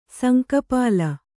♪ sanka pāla